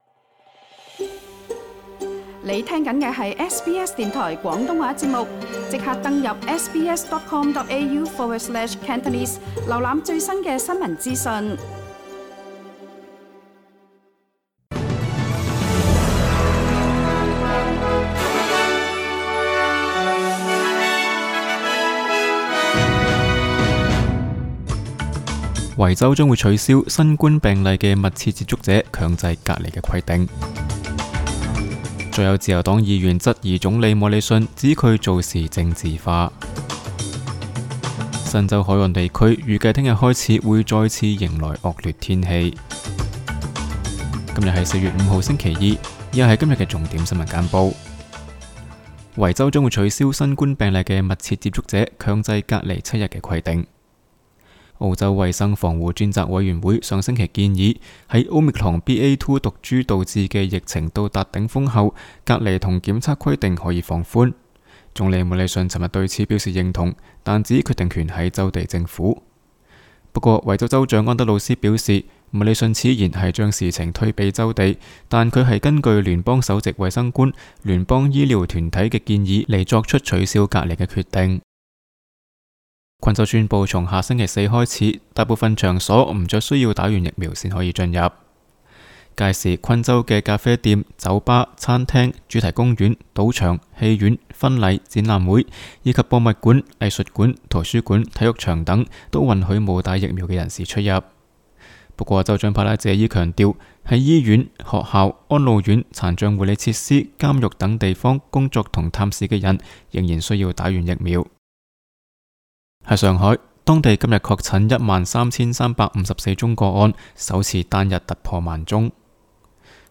SBS 廣東話節目新聞簡報 Source: SBS Cantonese